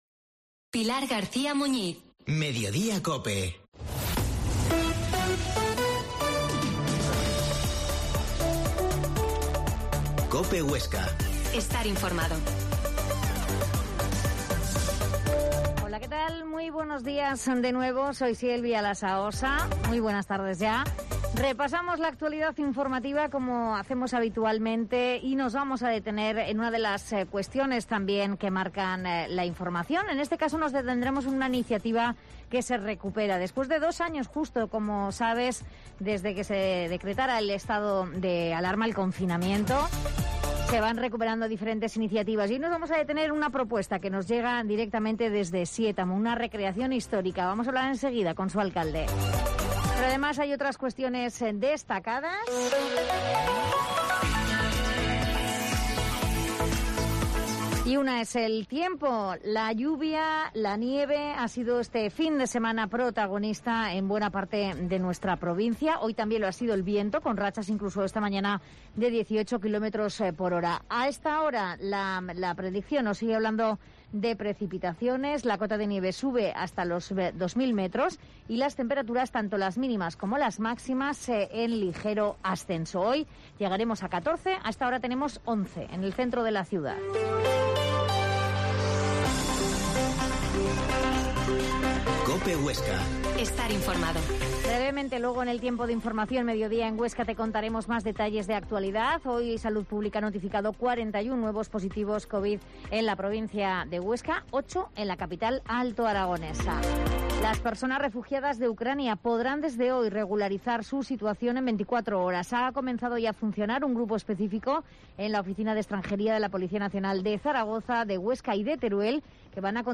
Mediodia en COPE Huesca 13.50h Entrevista a José Luis Usé, alcalde de Siétamo